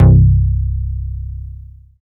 74 SJ BASS.wav